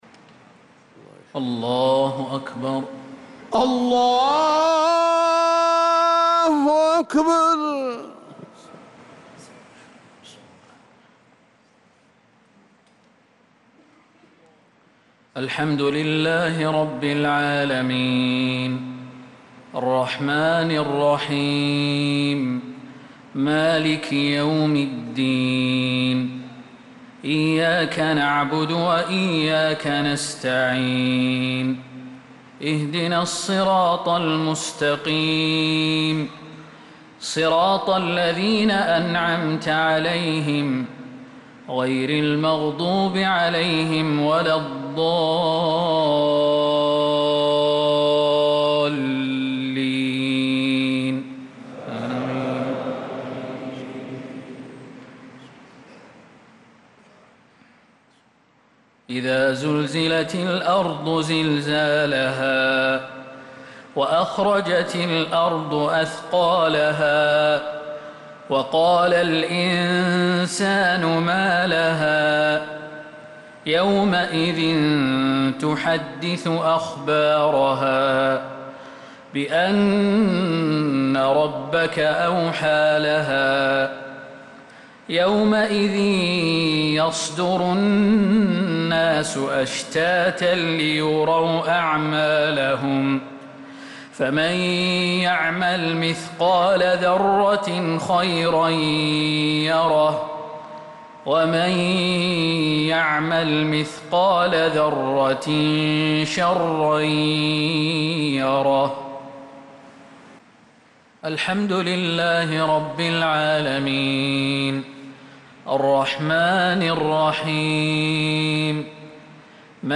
صلاة المغرب للقارئ خالد المهنا 20 محرم 1446 هـ
تِلَاوَات الْحَرَمَيْن .